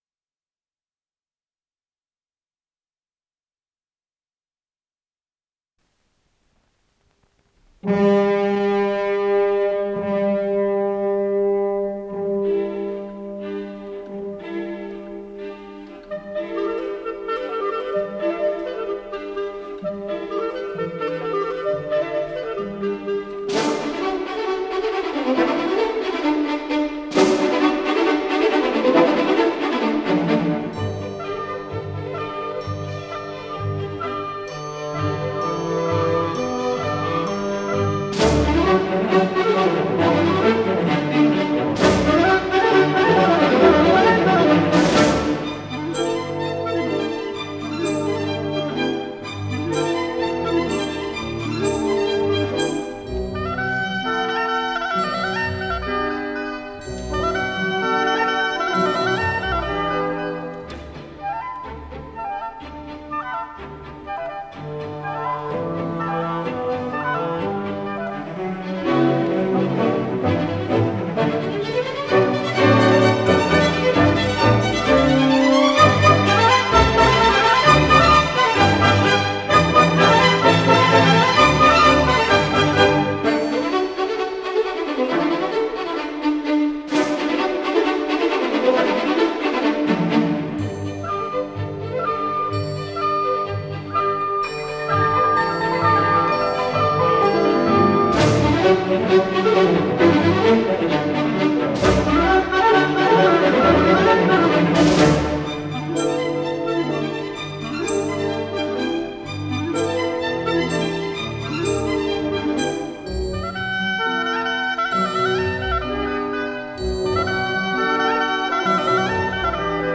Аллегро